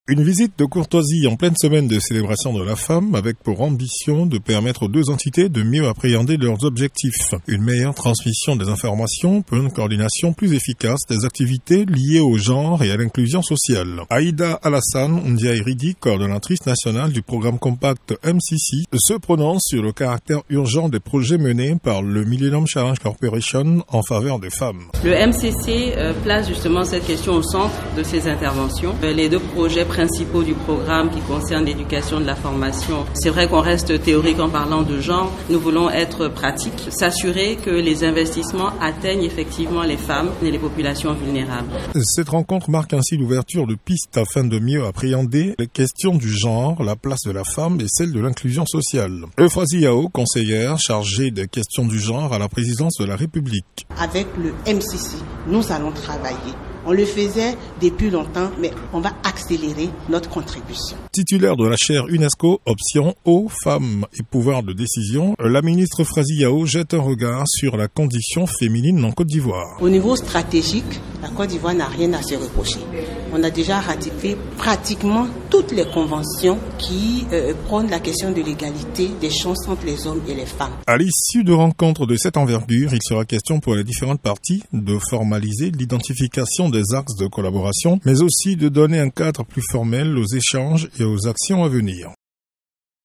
Compte rendu de ONUCI FM